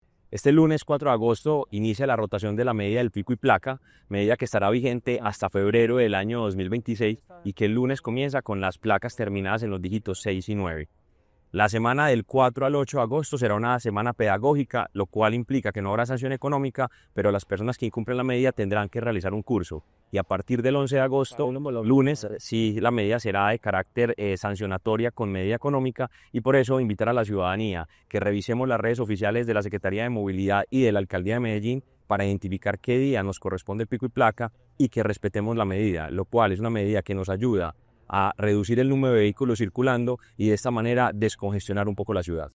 Declaraciones del secretario de Movilidad, Mateo González Benítez.
Declaraciones-del-secretario-de-Movilidad-Mateo-Gonzalez-Benitez..mp3